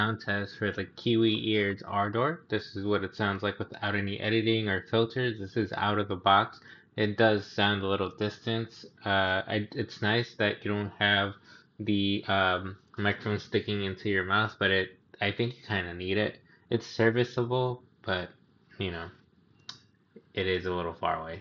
Mic Check
The headset does have a built in microphone, but the quality is disappointing. I like that it is discrete, but it does sound far away and there is some echo.
ardorsoundtest.mp3